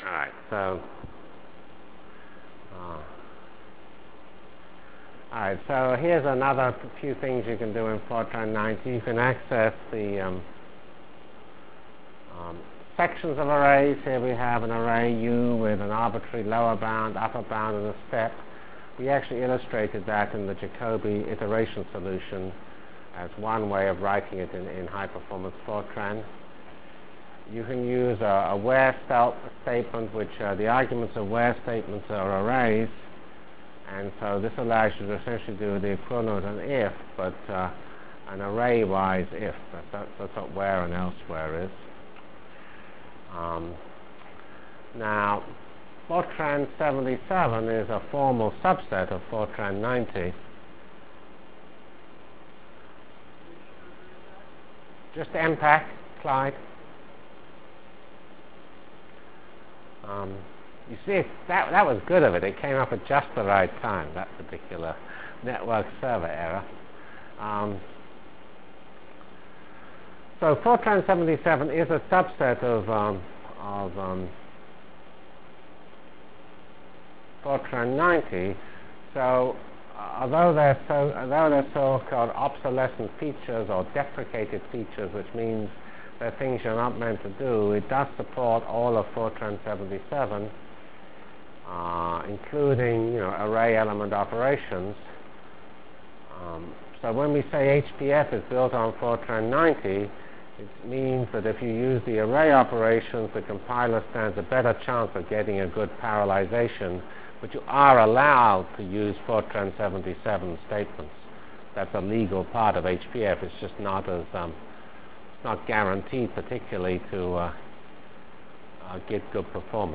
From CPS615-Introduction to F90 Features, Rationale for HPF and Problem Architecture Delivered Lectures of CPS615 Basic Simulation Track for Computational Science -- 24 September 96.